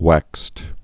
(wăkst)